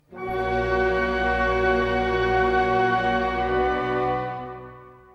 Ici la fréquence d'échantillonnage est de 96000 hz, et comme le fichier est du wav, le taux est de 16 bits.